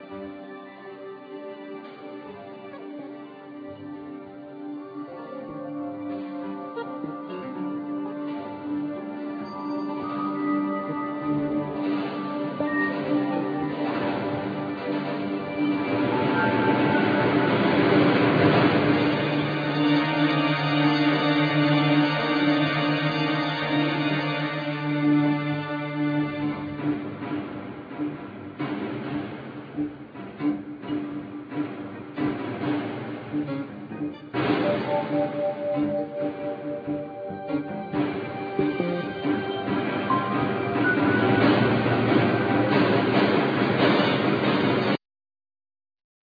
Keyboards,Synthesizers,Percussion,Vocal